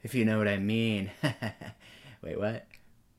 Category 😂 Memes
english female girl love saying speak speech talk sound effect free sound royalty free Memes